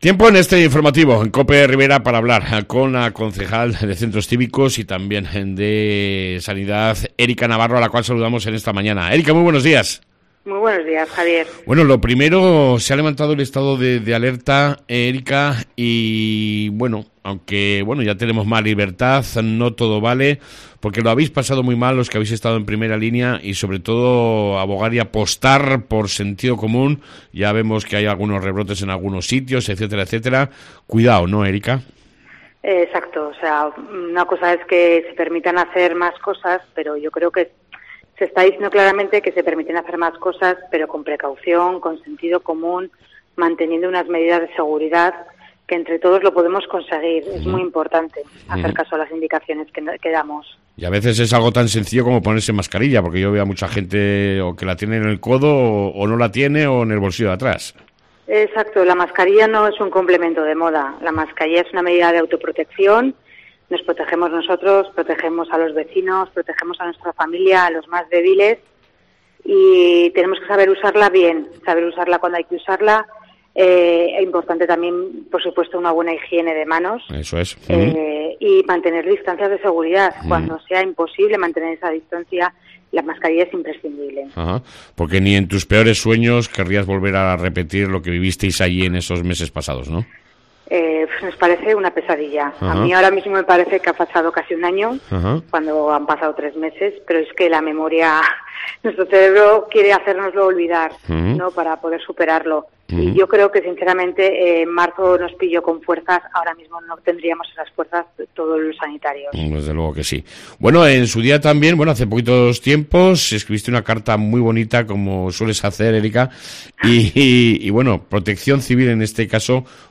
AUDIO: Entrevista con la concejal Erika Navarro